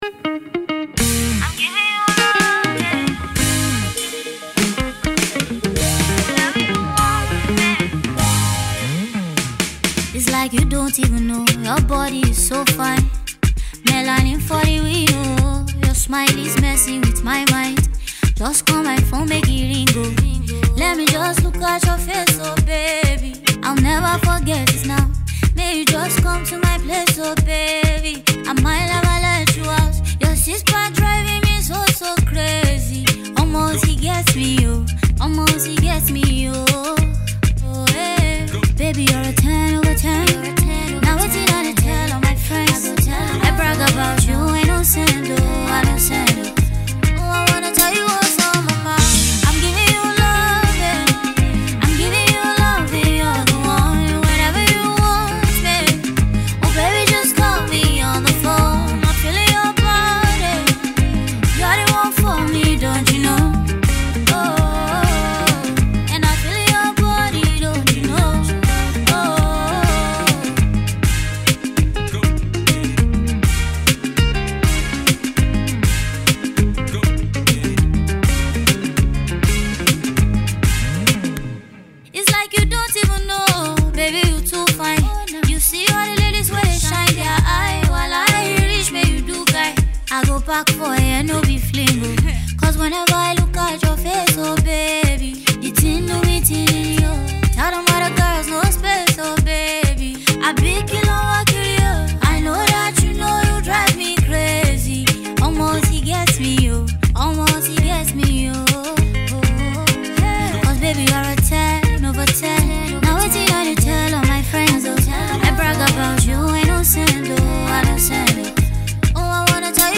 vocal powerhouse